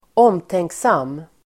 Ladda ner uttalet
Uttal: [²'åm:tengksam:]